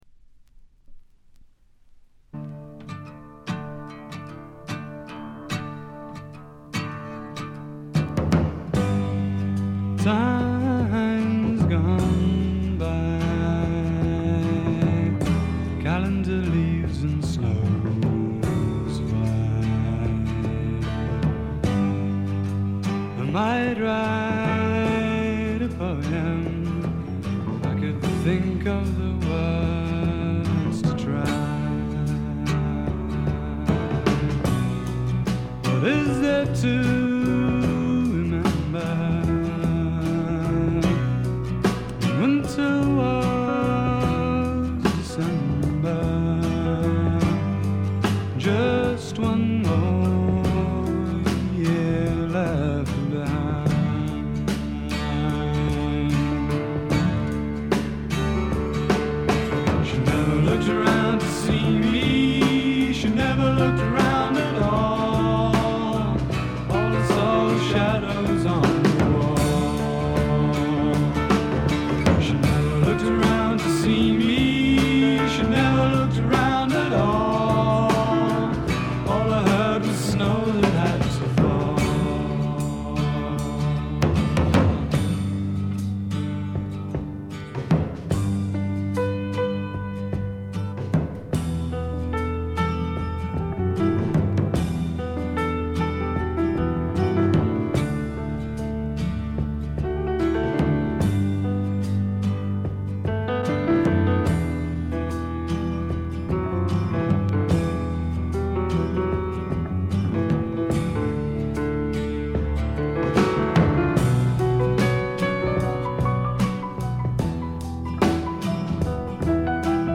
ほとんどノイズ感無し。
音の方はウッドストック・サウンドに英国的な香りが漂ってくるという、この筋の方にはたまらないものに仕上がっています。
試聴曲は現品からの取り込み音源です。